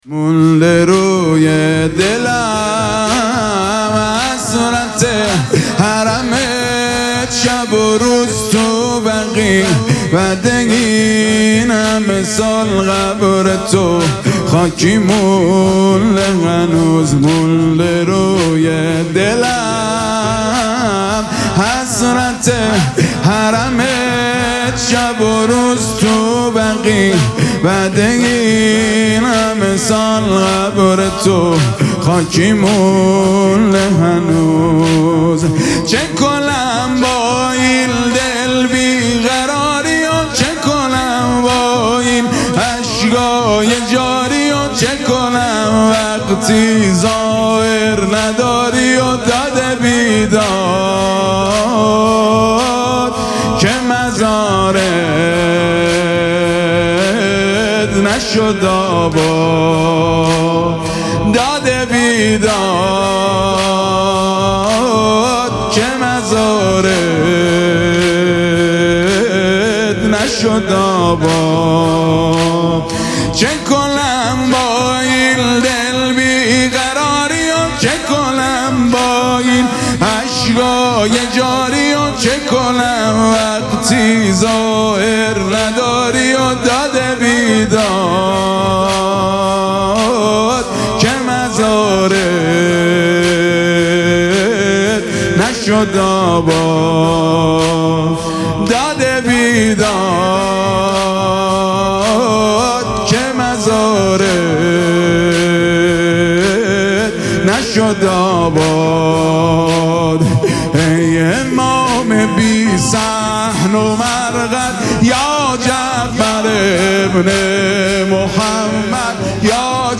دانلود مداحی
با نوای دلنشین